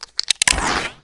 reload_shell_emp.ogg